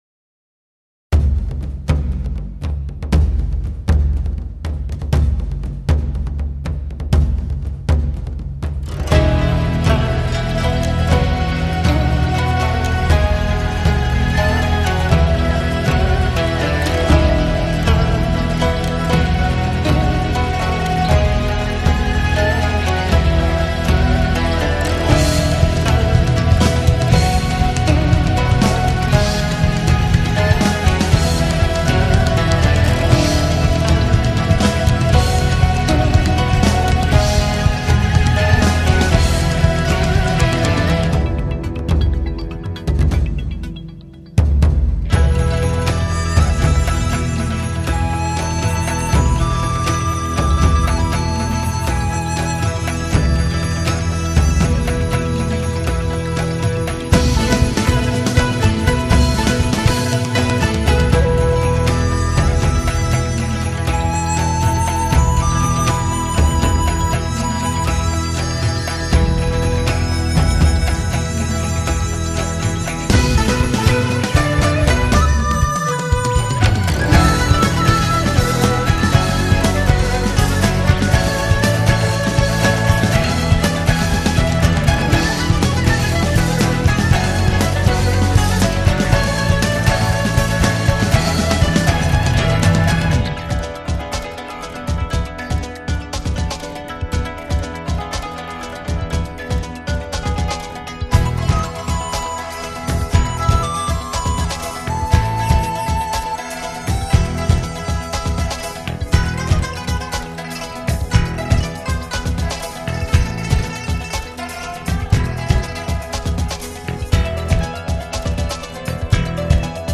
一種充滿現代氣息的聲音，如隨風潛入夜的春雨
專為汽車音響量身定做的HI-FI唱片，